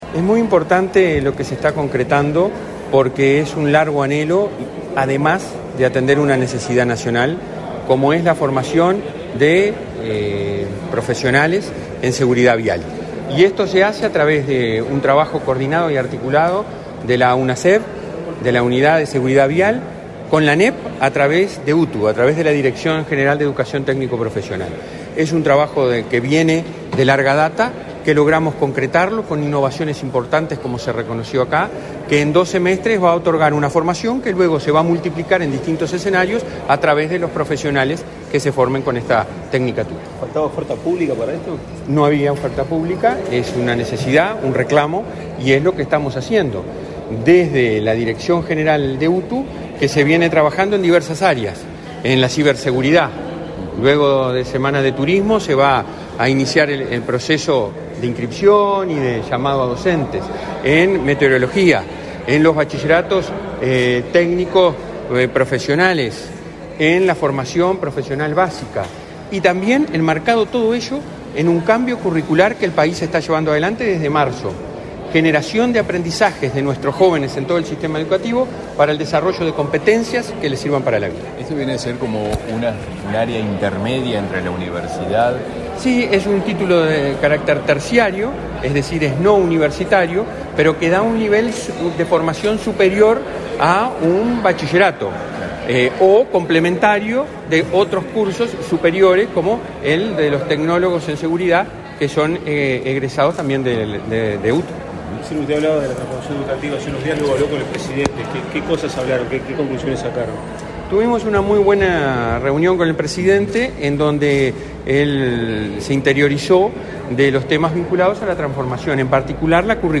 Declaraciones a la prensa del presidente del Codicen de la ANEP, Robert Silva